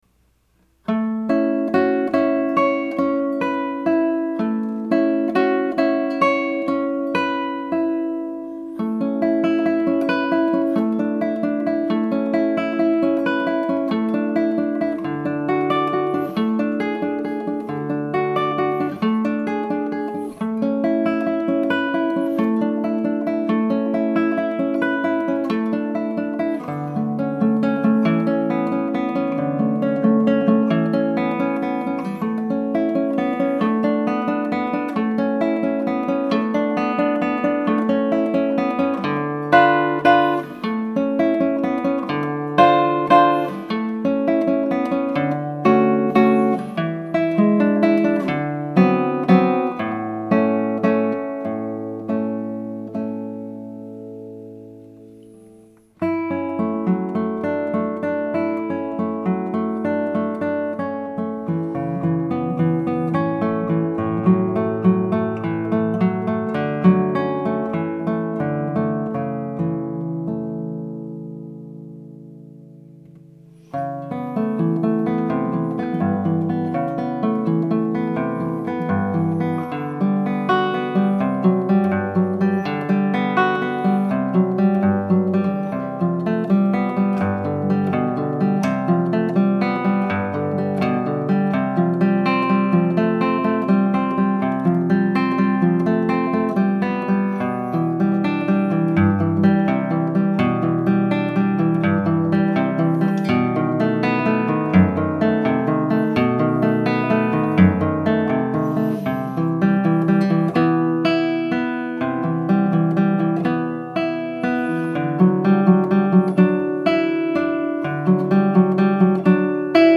ALT-CLASSICAL